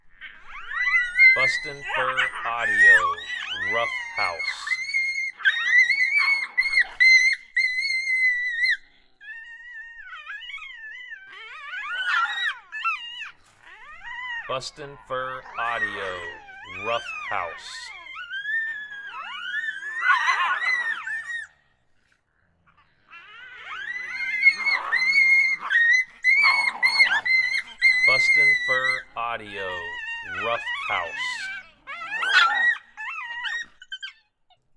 Male Coyote Moto and Female Coyote Tazzy having a serious scuffle, lots of aggressive growling and squalling in this sound.